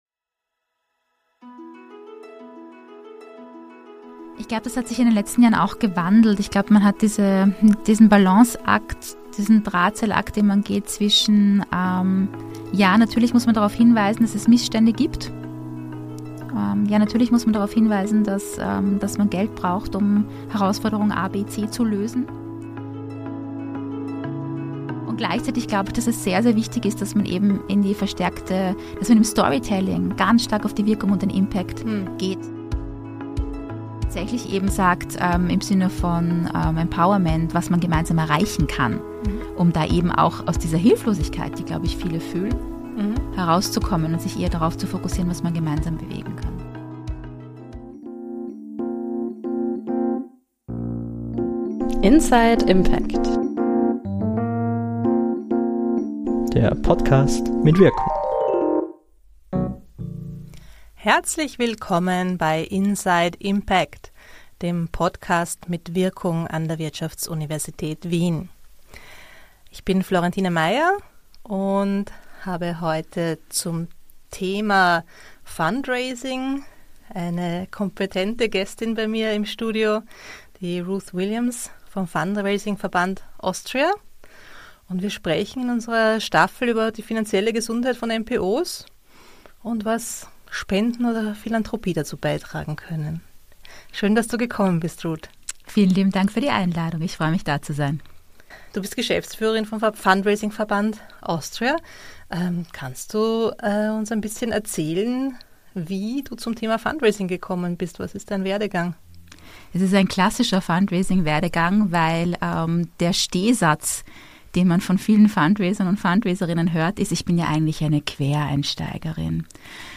Fundraising und Spenden - Im Gespräch mit